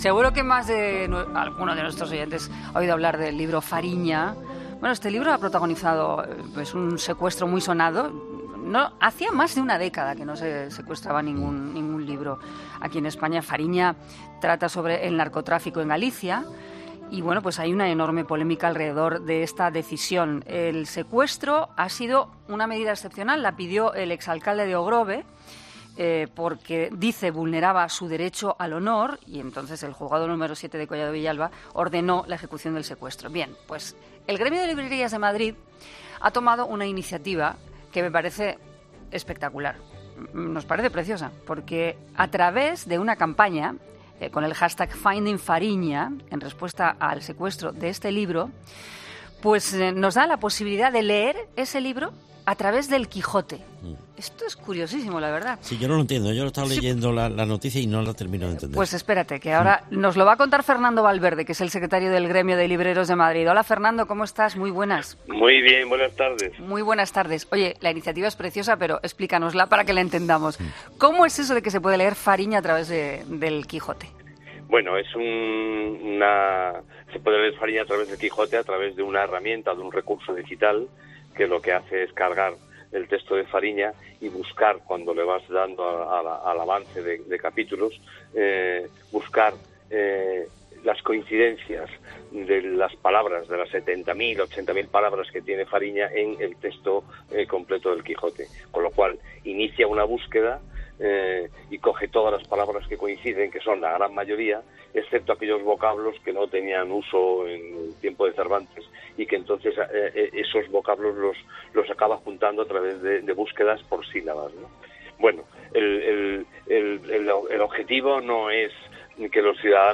'Herrera en COPE' Entrevista